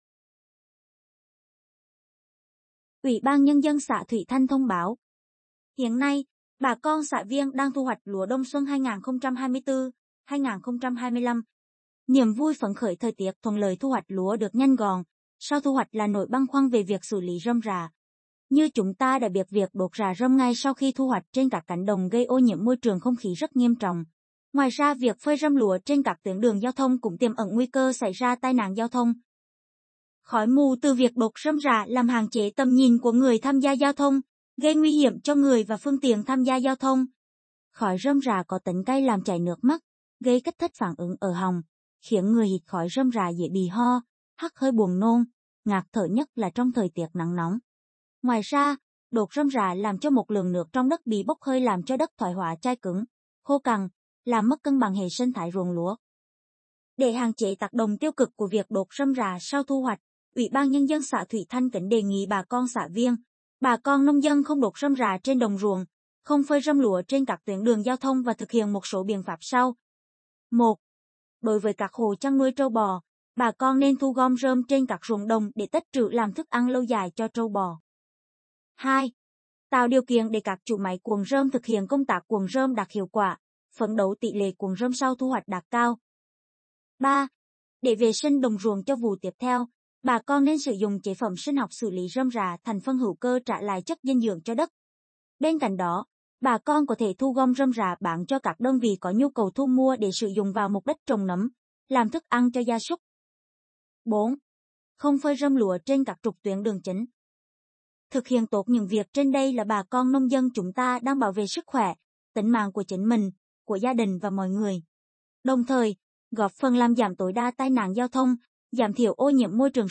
Đồng thời, trong những ngày vừa qua, UBND xã đã tăng cường thông báo, truyên truyền, vận động người dân không đốt rơm, rạ sau thu hoạch trên Đài Truyền thanh của xã (có file thông báo kèm theo).
thong_bao_tuyen_truyen.mp3